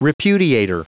Prononciation du mot : repudiator